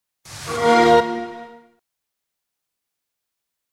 SFX – XBOX LOGO
SFX-XBOX-LOGO.mp3